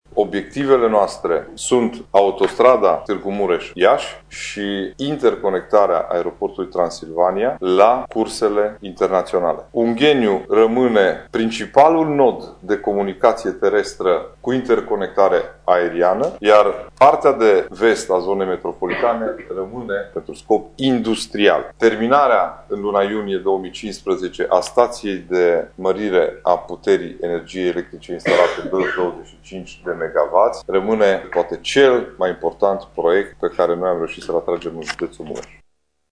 Investiţia totală pentru cele două drumuri este de 500 de milioane de euro, a precizat preşedintele CJ Mureş, Ciprian Dobre, la întâlnirea de final de an pe care a avut-o astăzi cu reprezentanţii presei.